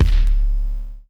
JJKicks (11).wav